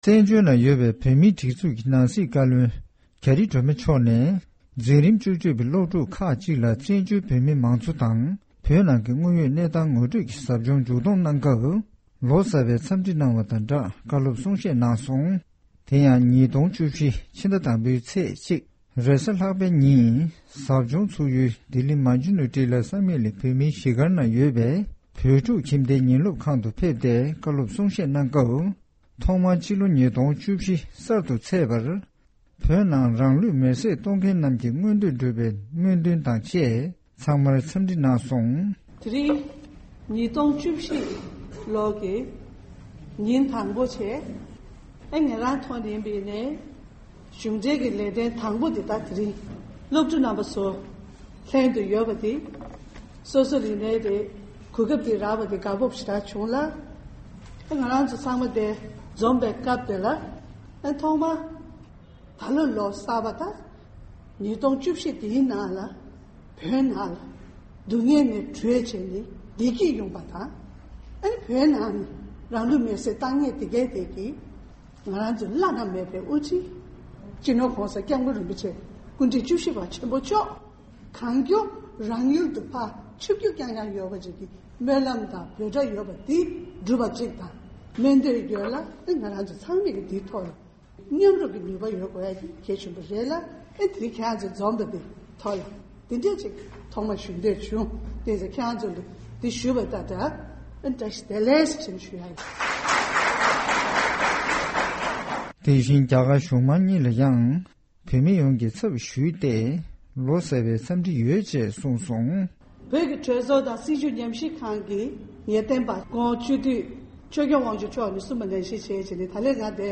ནང་སྲིད་བཀའ་བློན་མཆོག་གིས་བོད་པའི་སློབ་ཕྲུག་ལ་གསུང་བཤད་གནང་ཡོད་པ།
རྒྱ་གར་གྱི་རྒྱལ་ས་ལྡི་ལི་གསར་པའི་ནང་འཛིན་རིམ་བཅུ་གཅིག་པར་སླེབས་པའི་བོད་པའི་སློབ་ཕྲུག་གི་དམིགས་བསལ་གཟབ་སྦྱོང་ཞིག་འཚོགས་ཡོད་པ་དེའི་མཇུག་བསྡོམས་ཀྱི་མཛད་སྒོར། དབུས་བོད་མིའི་སྒྲིག་འཛུགས་ཀྱི་ནང་སྲིད་བཀའ་བློན་རྒྱ་རི་སྒྲོལ་མ་ལགས་ཕེབས་ཏེ་སློབ་ཕྲུག་རྣམས་ལ་ཕྱི་ལོ་གསར་ཚེས་ཀྱི་འཚམས་འདྲི་གནང་བ་དང་སྦྲགས།